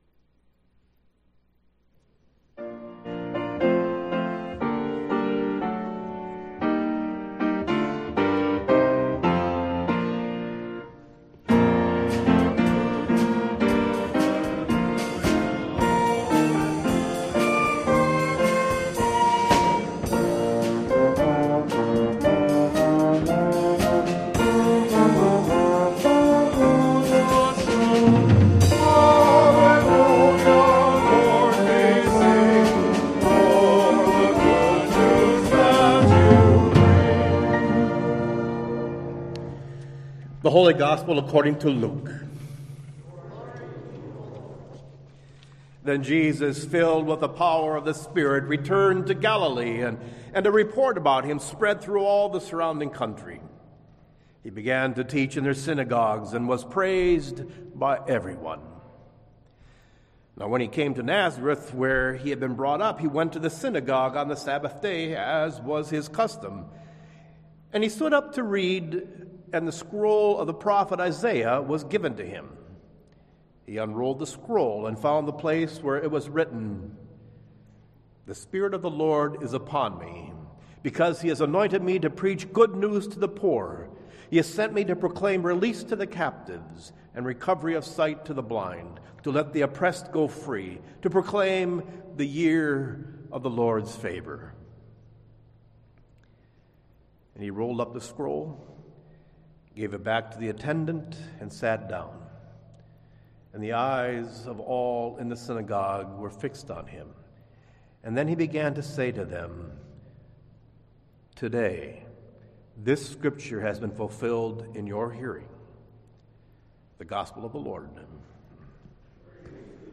Worship Services | Christ The King Lutheran Church